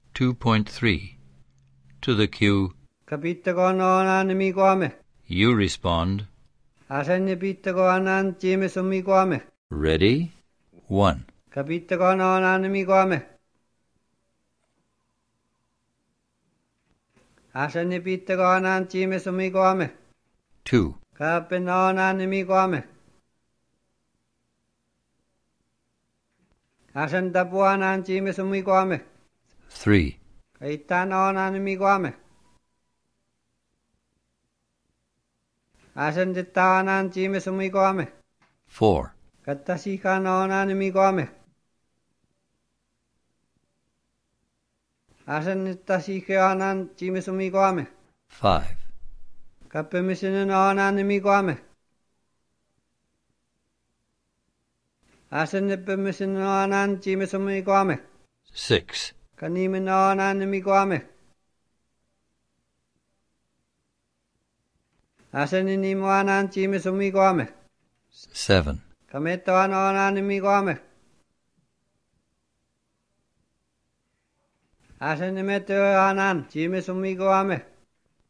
In this section you will hear a series of pronunciation and speaking drills.